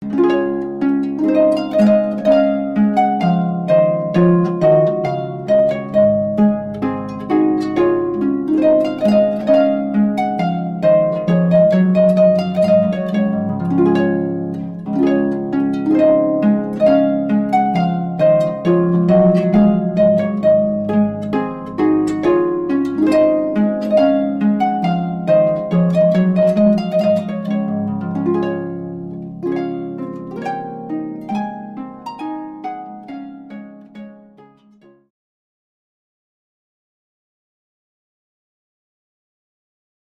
Harpist Arrival Of The Queen Of Sheba